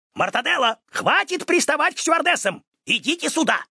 Образцы озвучания, прошедшие визирование у Супера и допущенные к опубликованию: